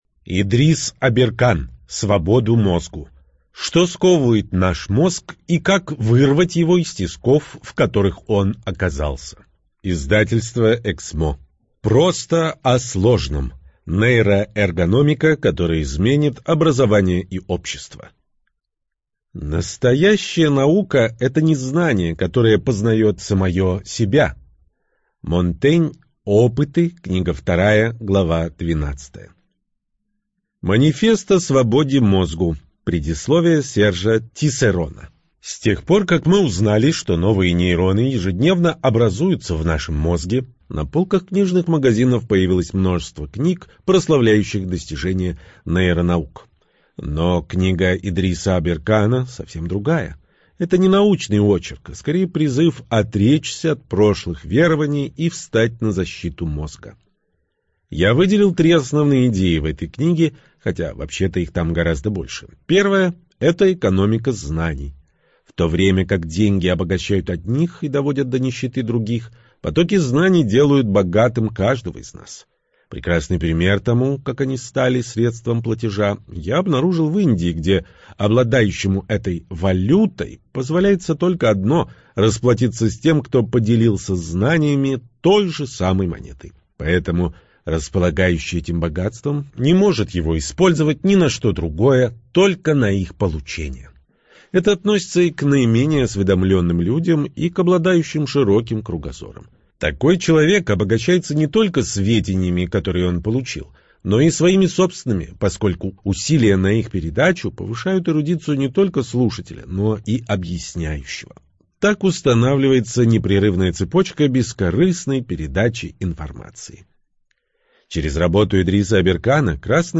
ЖанрНаучно-популярная литература, Психология